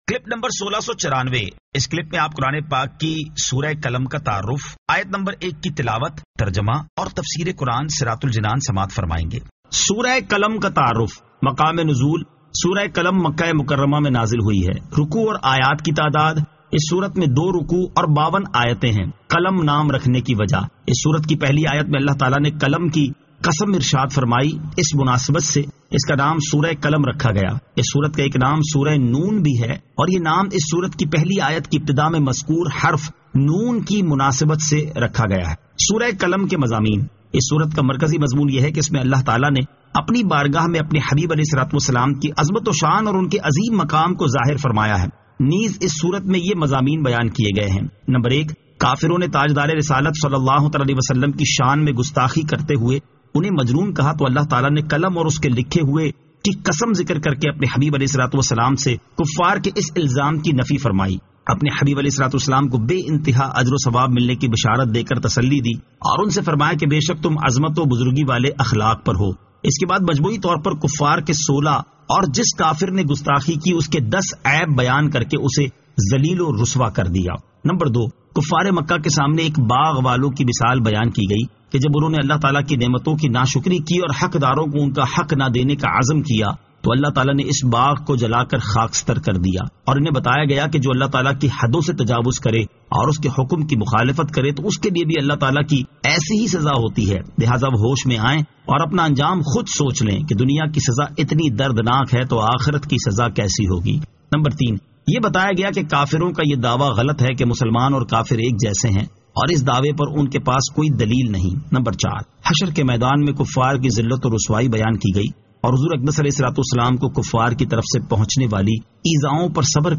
Surah Al-Qalam 01 To 01 Tilawat , Tarjama , Tafseer